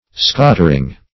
Search Result for " scottering" : The Collaborative International Dictionary of English v.0.48: Scottering \Scot"ter*ing\, n. The burning of a wad of pease straw at the end of harvest.